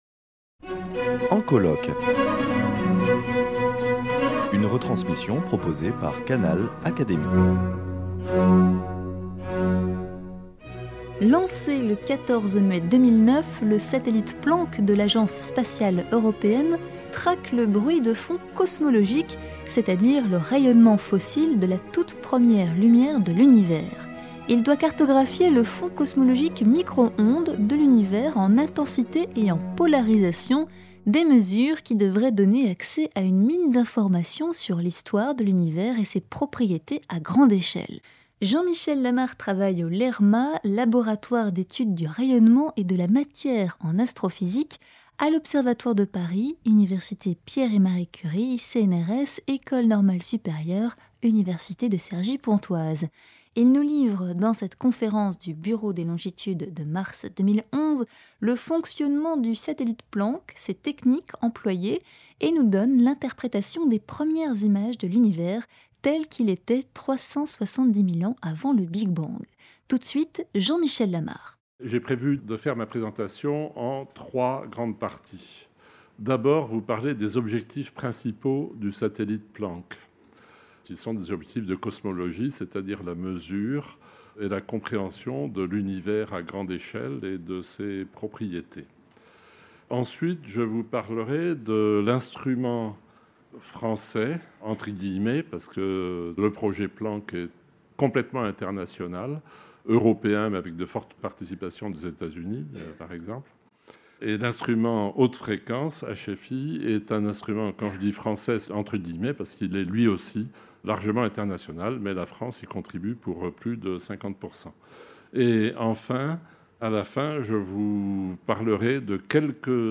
Au cours de sa conférence donnée au Bureau des longitudes en mars 2011